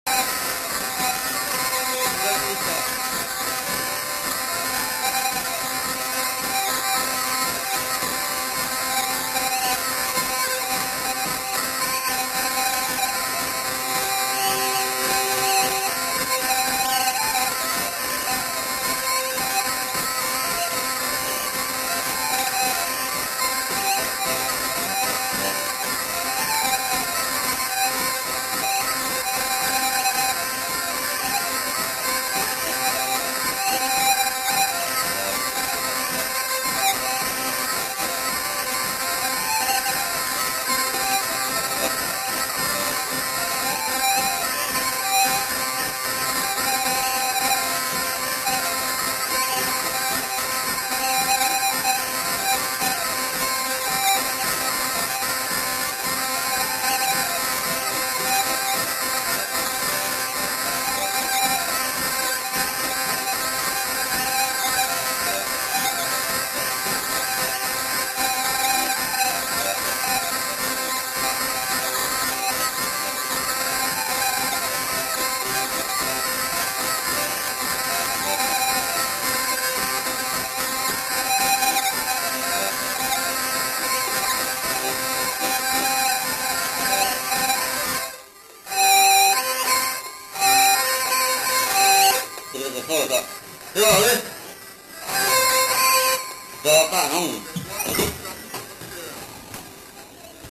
Polka
Aire culturelle : Gabardan
Lieu : Oeyreluy
Genre : morceau instrumental
Instrument de musique : vielle à roue
Danse : polka